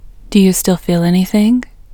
IN – the Second Way – English Female 21